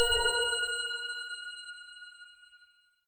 coin_to_gem_but_actually_gem.ogg